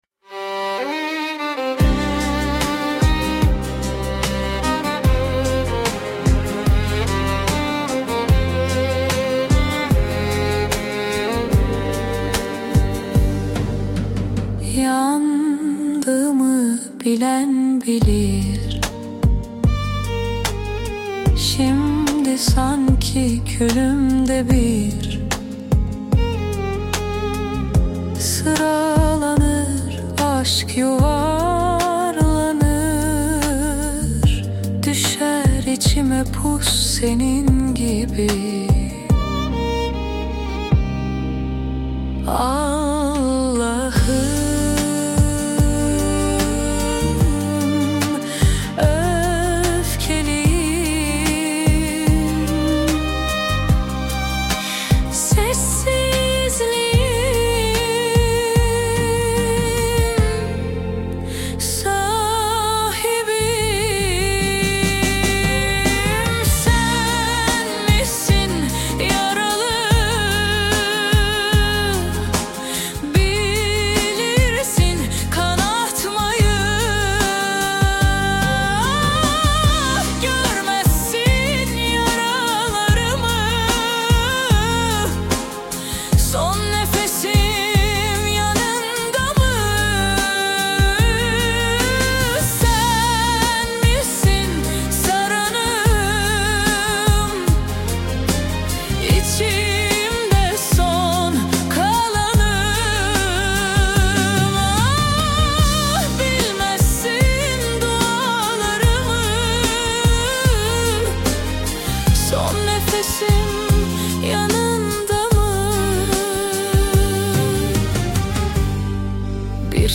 Tür : Alaturka Pop